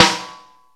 EMX SNR 9.wav